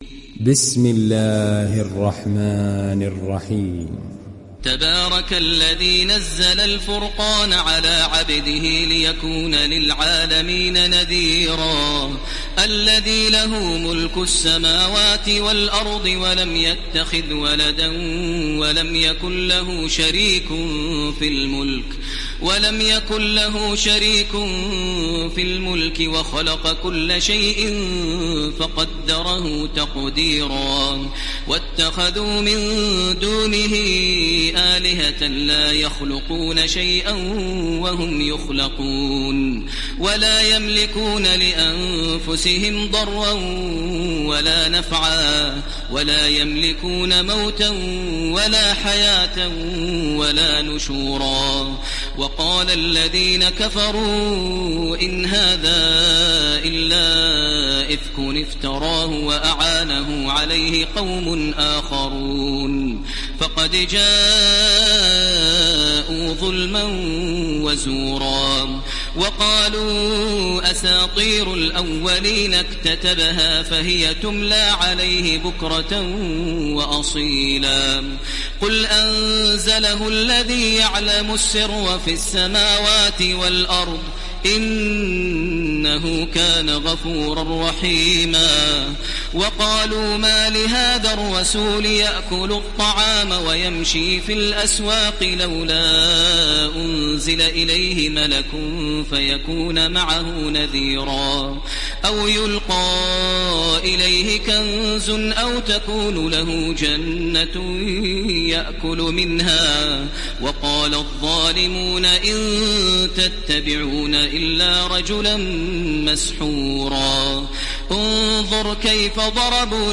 Download Surat Al Furqan Taraweeh Makkah 1430